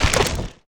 creaking_attack3.ogg